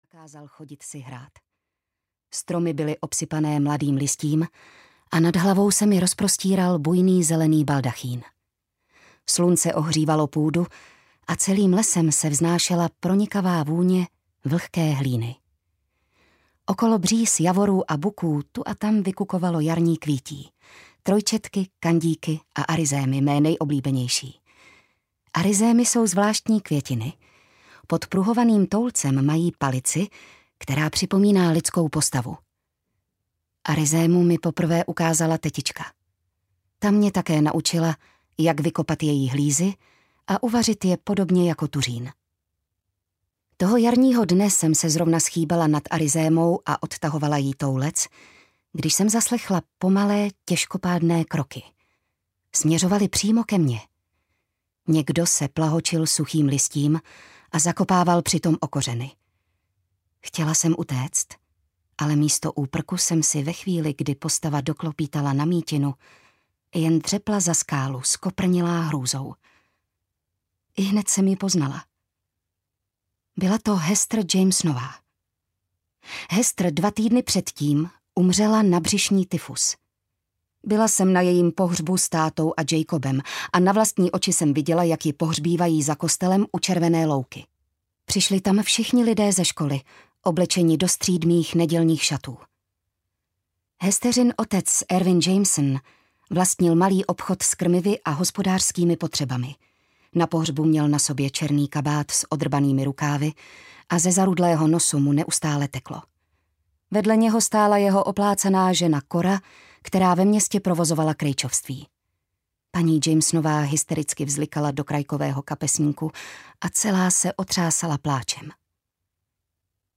Zimní lidé audiokniha
Ukázka z knihy